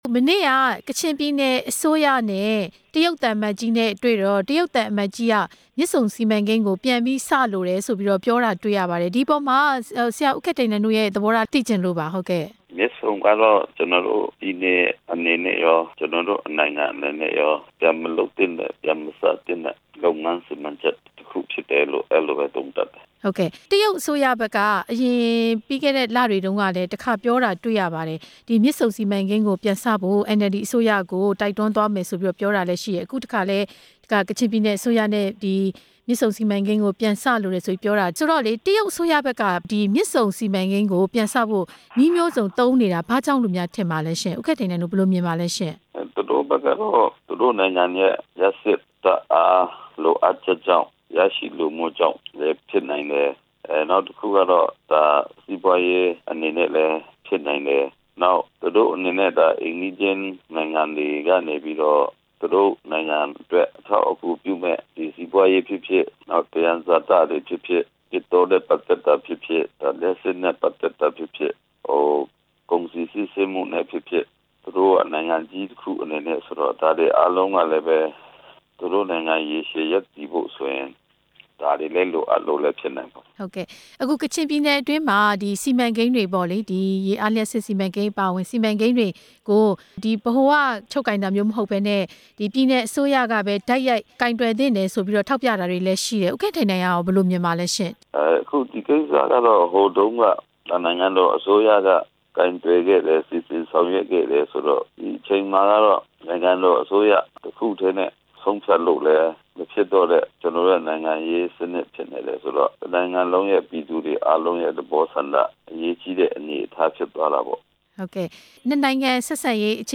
မြစ်ဆုံစီမံကိန်း ပြန်စဖို့ တရုတ်ရဲ့တောင်းဆိုချက်၊ ကချင်ပါတီခေါင်းဆောင်နဲ့ မေးမြန်းချက်